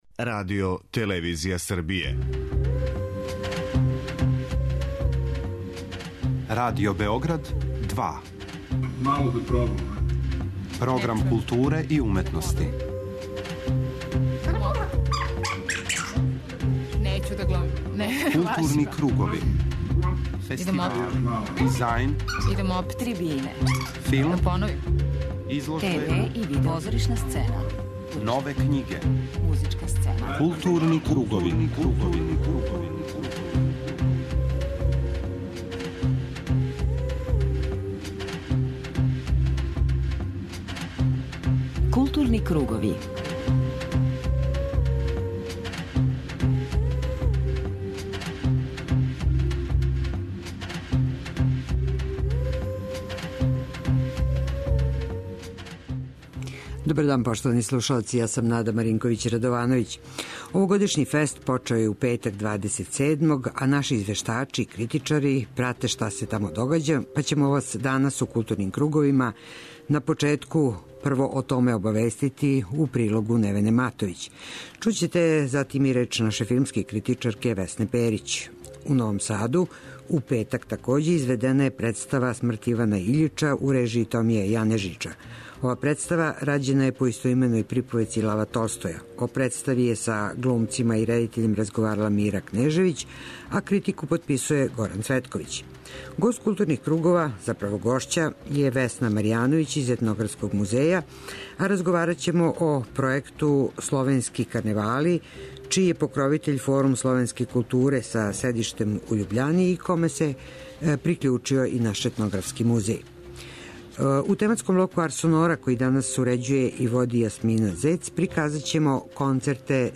преузми : 52.05 MB Културни кругови Autor: Група аутора Централна културно-уметничка емисија Радио Београда 2.